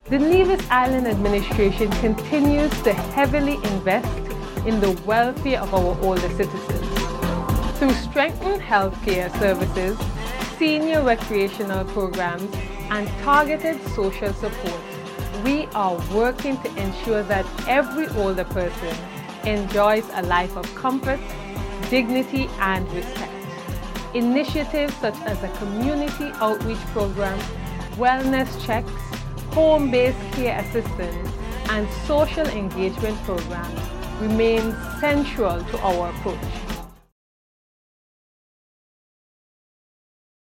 Nevis’ Minister of Health & Social Services, the Hon. Jahnel Nisbett.